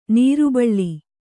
♪ nīru baḷḷi